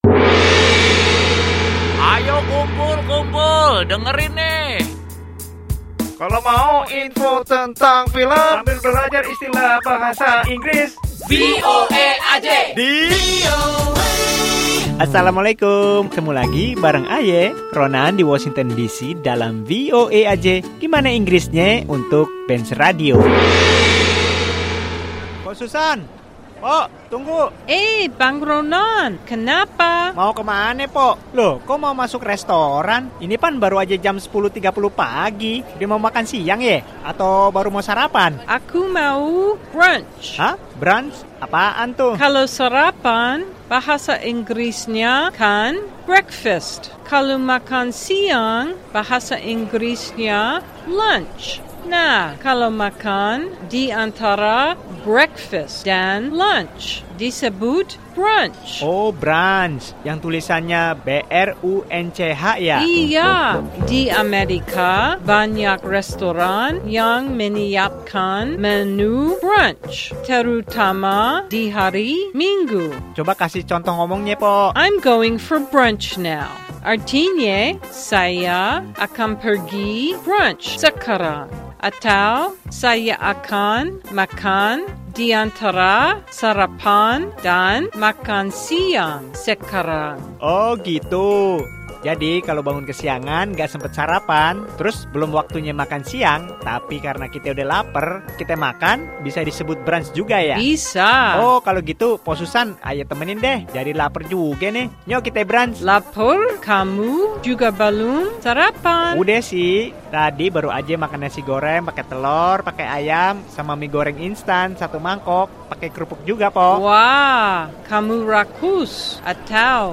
Simak percakapan kali ini mengenai arti, cara pengucapan dan penggunaannya dalam bahasa sehari-hari untuk kata "Brunch".